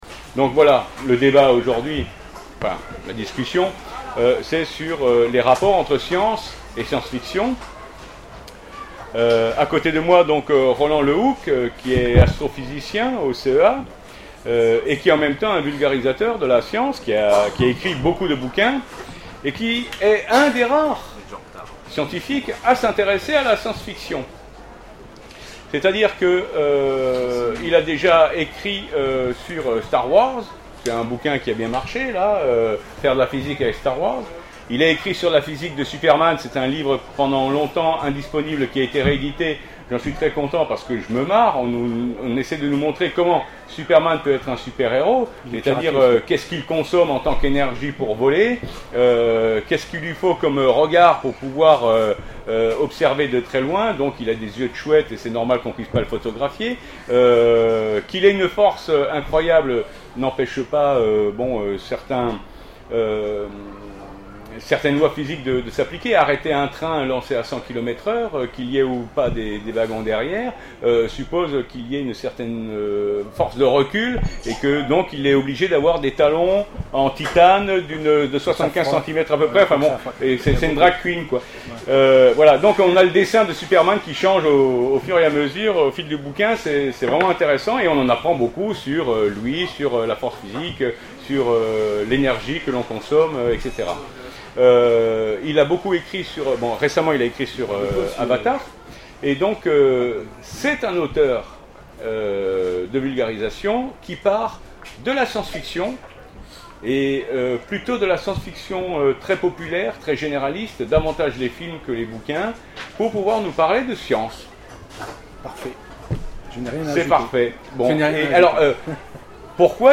Les Futuriales 2013 : Conférence La SF sous les feux de la science